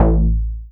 I_SQBass.wav